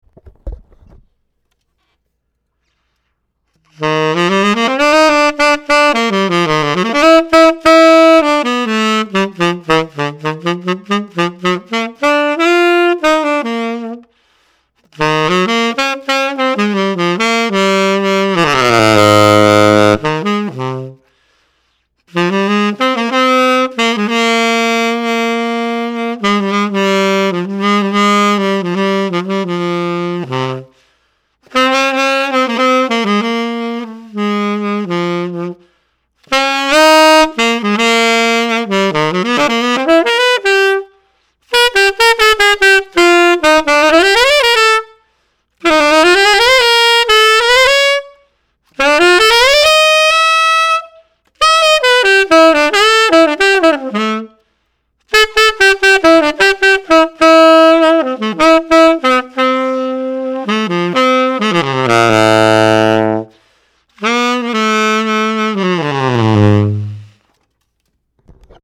I will be reviewing the Viking M21 Swing Sonic tenor saxophone based on its appearance, build quality, tone & response, action, and intonation.
I found the Viking M21 Swing Sonic was free blowing from low Bb to high F#. I found the bottom end to be full and neutral in terms of not too dark or bright, the middle section was also neutral but I found a little bit of resistance, and the upper register leaned towards the brighter side and really cut. I found the tone overall to lean towards a brighter straight ahead sound compared to an edgy dark sound.
Me Play-Testing the M21 Swing Sonic